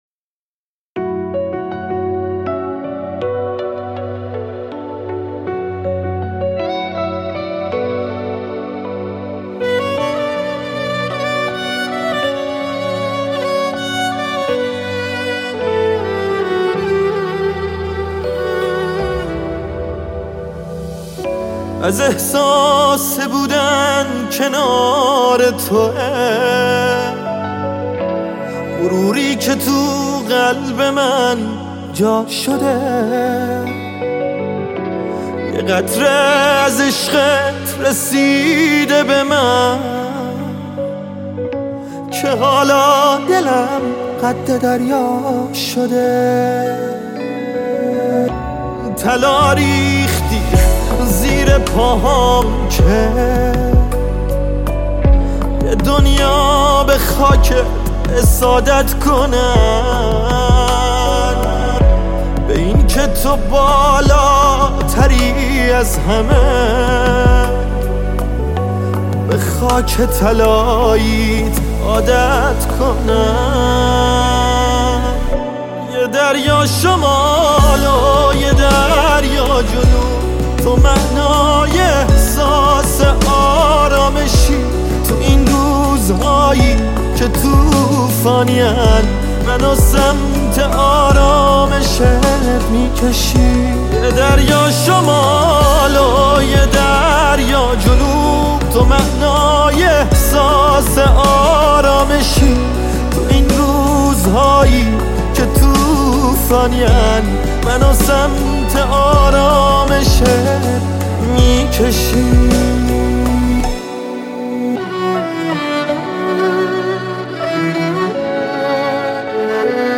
پاپ وطن پرستی